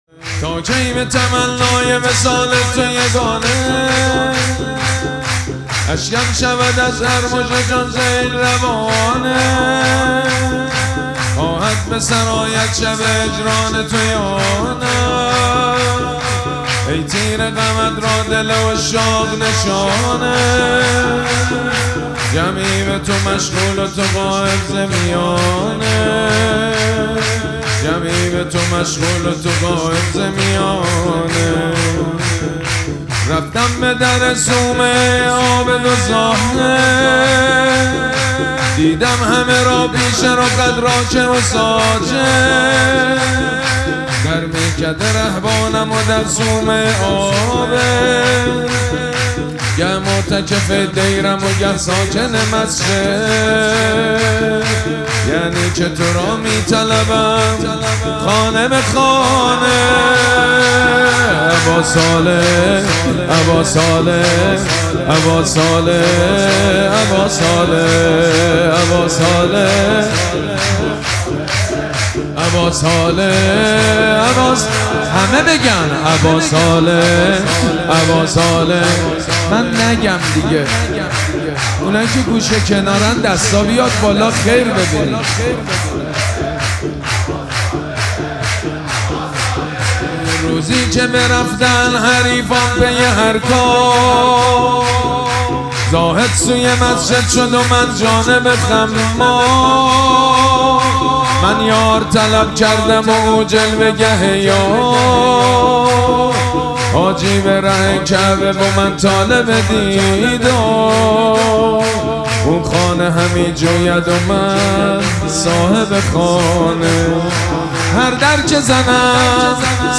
مراسم جشن ولادت حضرت صاحب الزمان (عج)
سرود
مداح